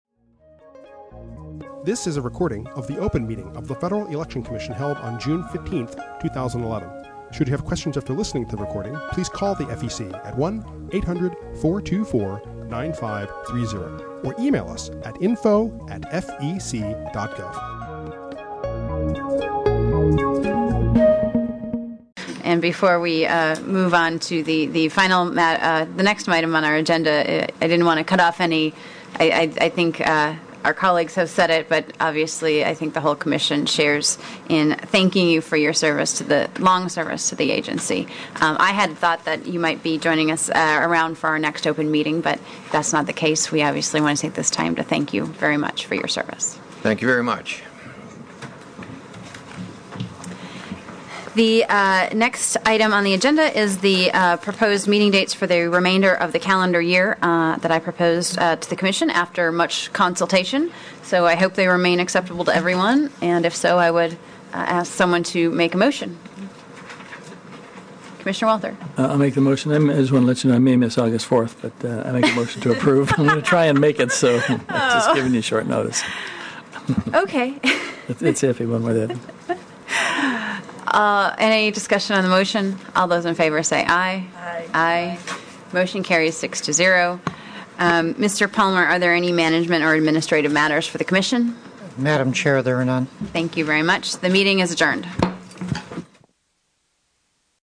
June 15, 2011 open meeting
The Commission considers new regulations, advisory opinions and other public matters at open meetings, which are typically held on Thursdays at 10:00 a.m. at FEC headquarters, 1050 First Street NE, Washington, DC.